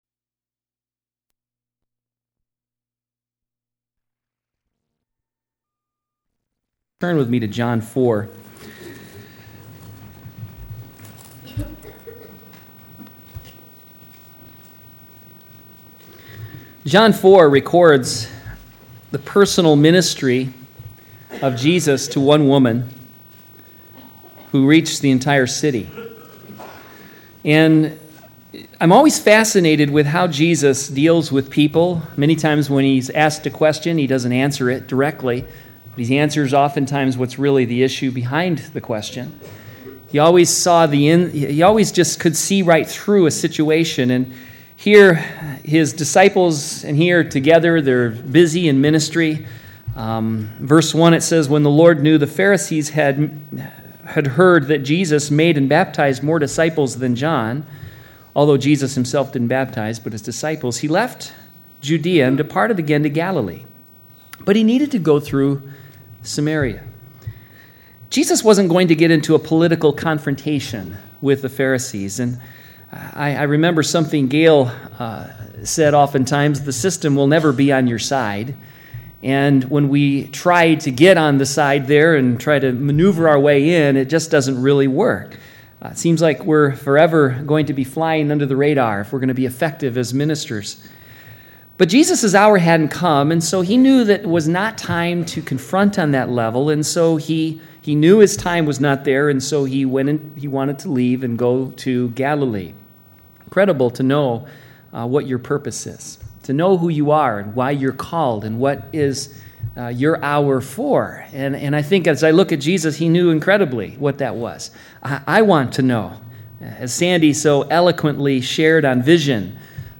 2002 DSPC Conference: Pastors & Leaders Date